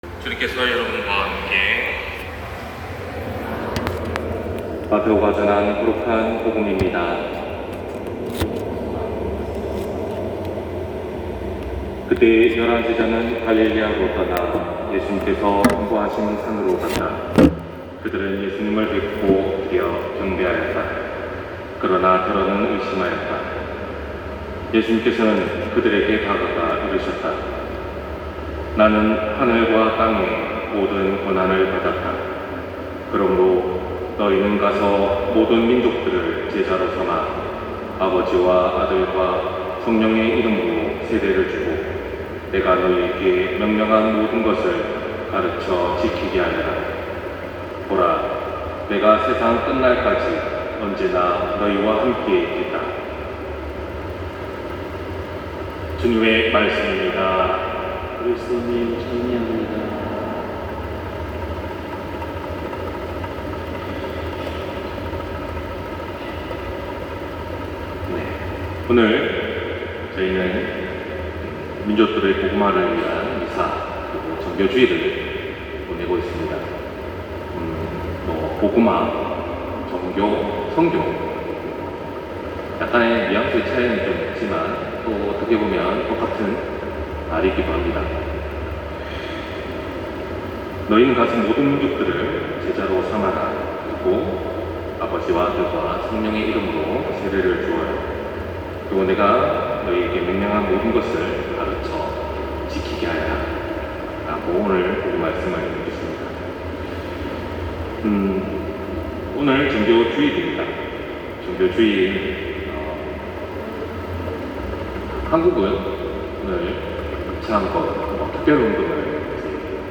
251018 신부님 강론 말씀